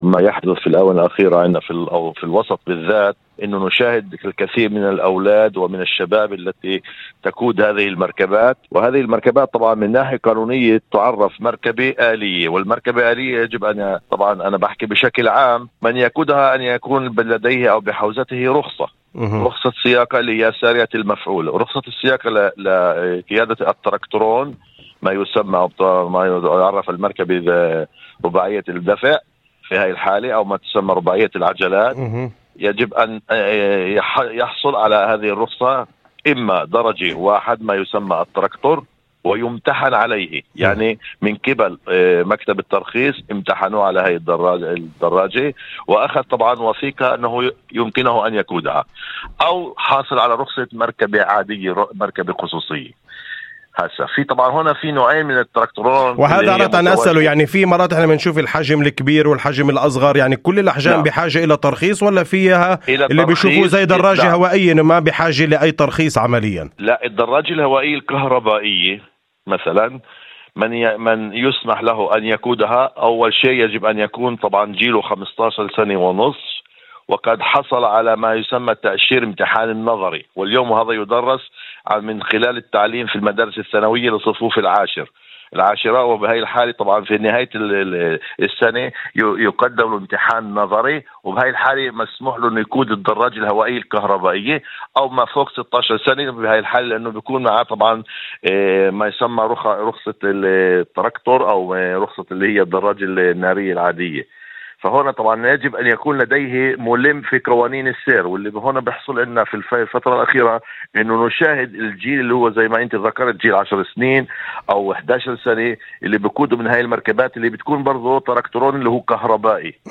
في مداخلة هاتفية ضمن برنامج "أول خبر" على إذاعة الشمس